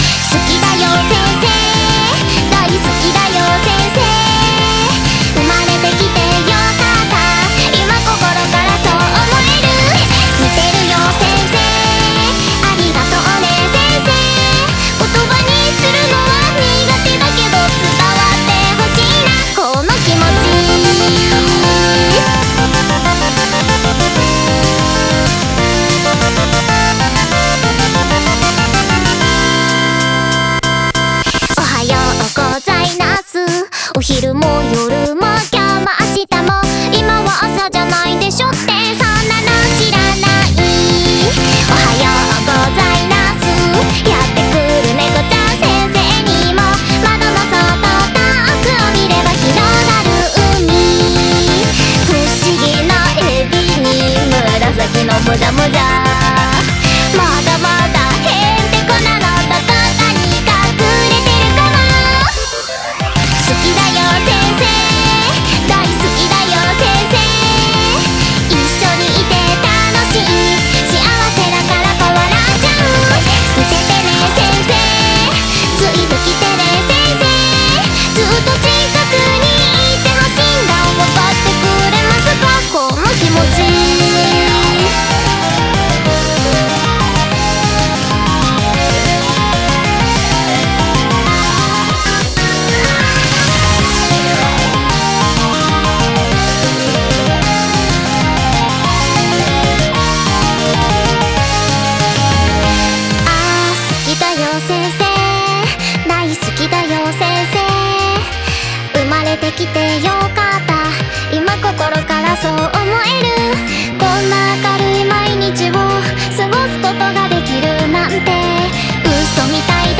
applause.wav